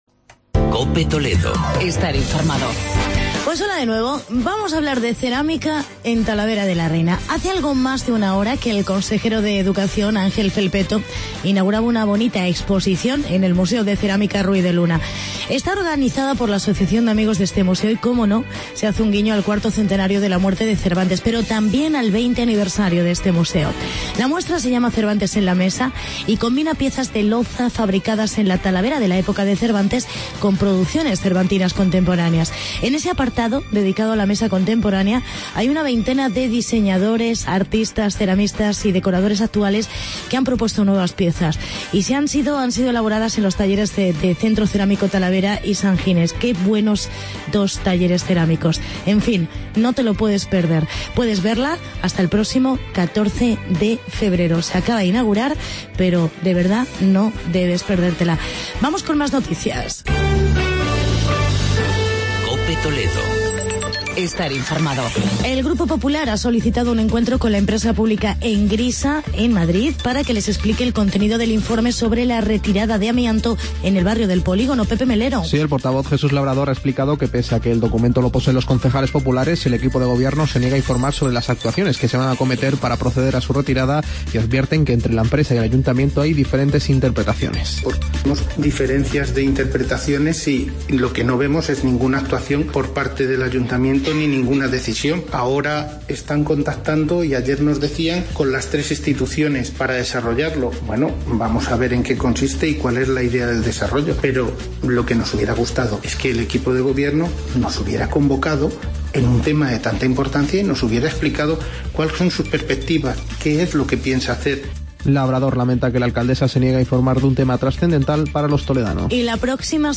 Actualidad y entrevista con Felipe Pulido, concejal de Castillo de Bayuela sobre el Mercado Cervantino.